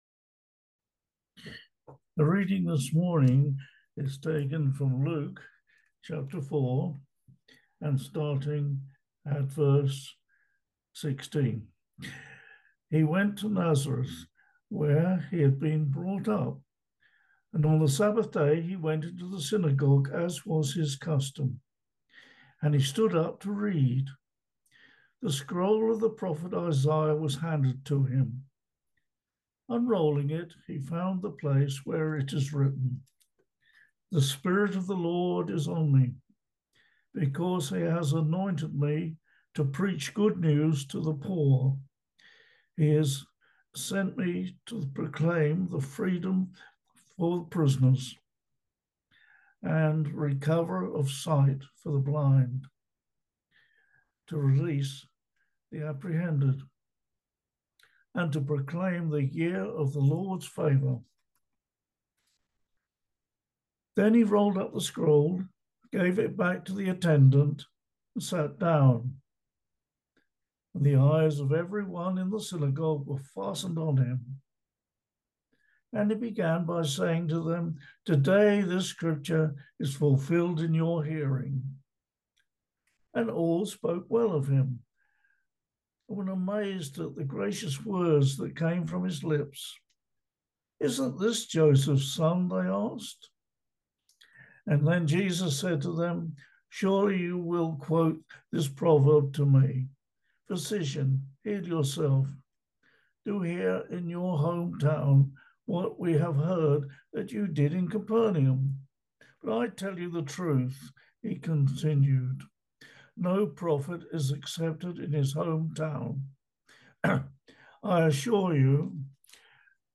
Easter Sunday